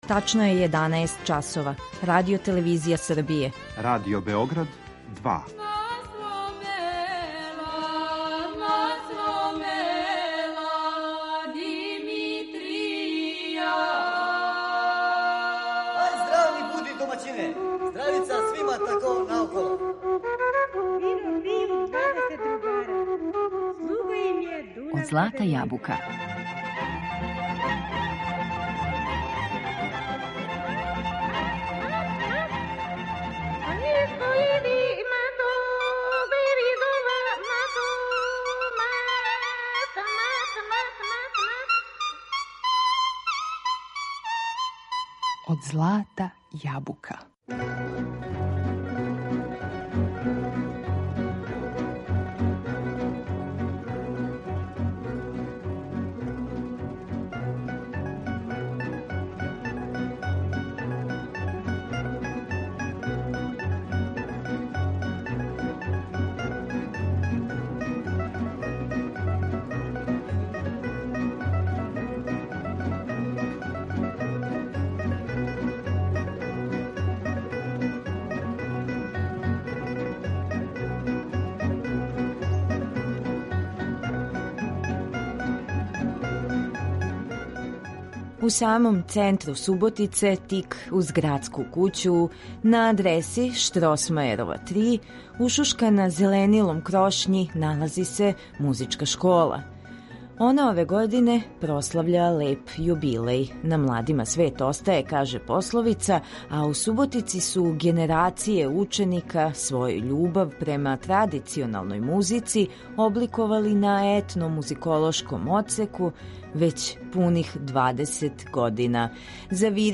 У емисији Од злата јабука слушамо најлепше снимке традиционалних песама у извођењу ученика Музичке школе Суботица.